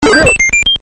お聞きの通り、SE丸パクリです。
このBGM・SEが使用されているタイトルをお答えください。